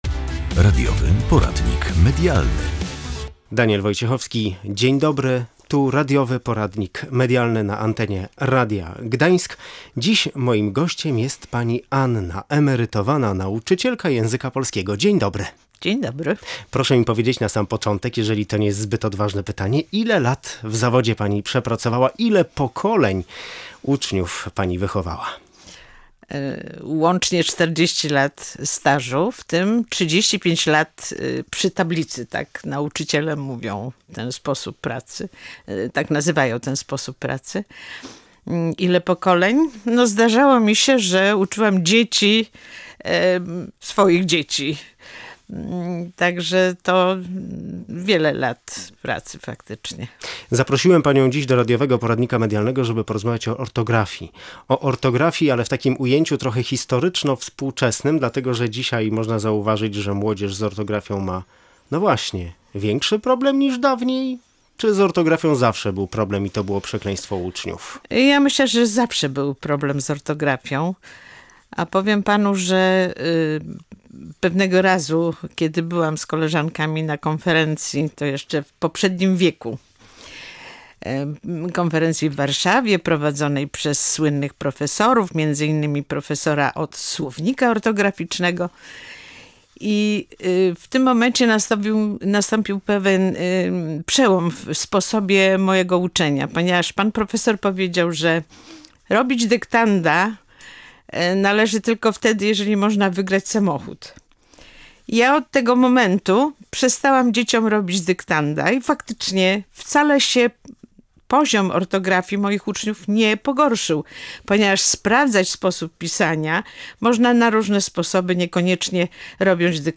Opowiada emerytowana nauczycielka